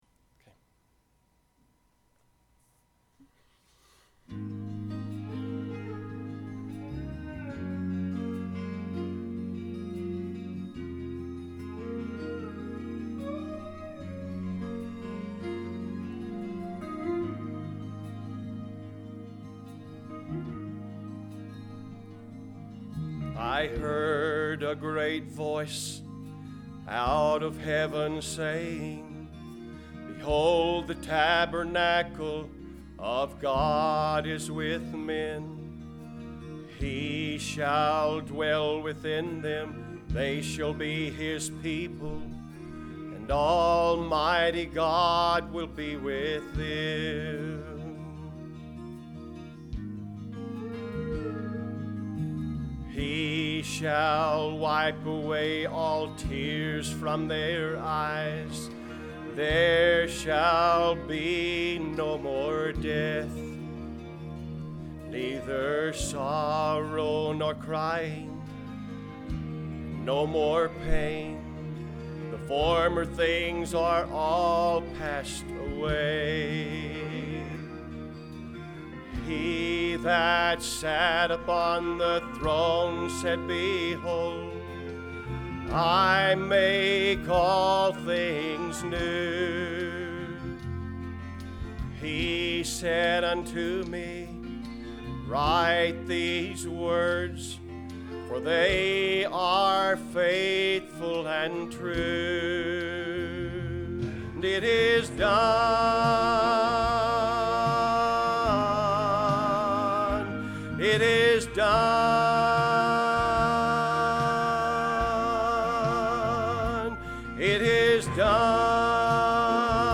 Filed Under: Sermons Tagged With: Corinthians